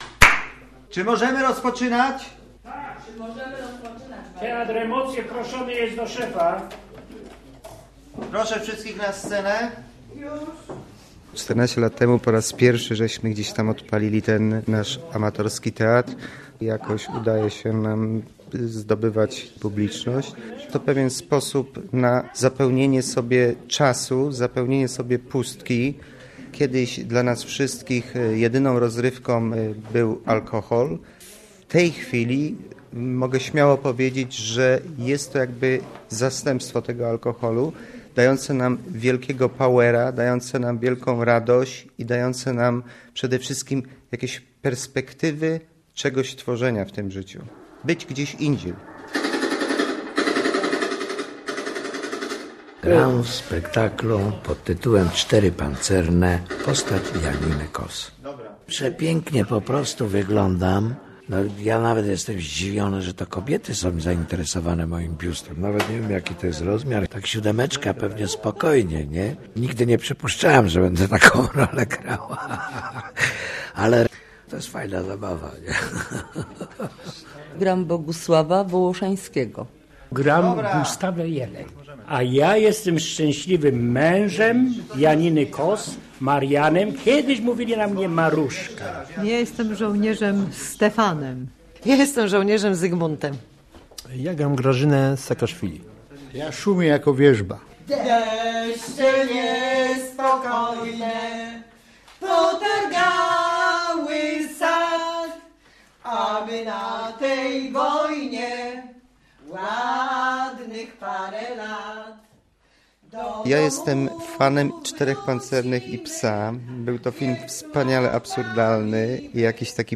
Uśpione aktywa - reportaż - Radio Poznań